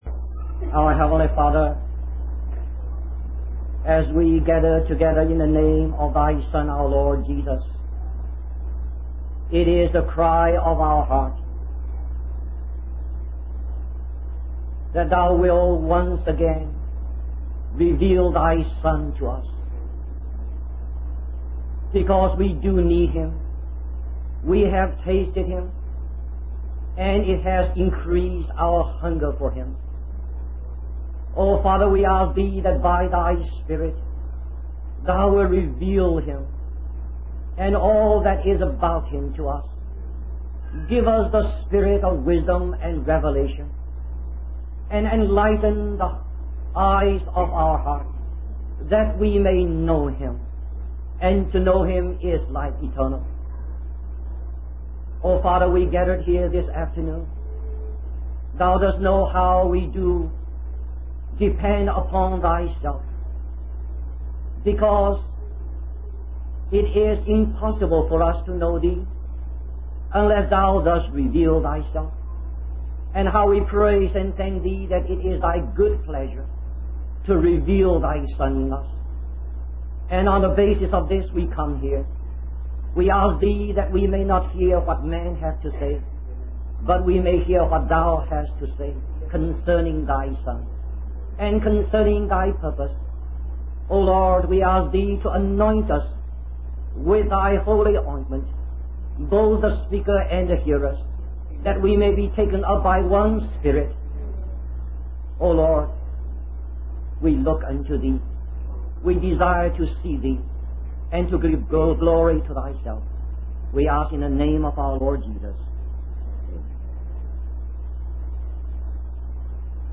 In this sermon, the speaker emphasizes the importance of living in the body of Christ and being subject to the headship of Christ.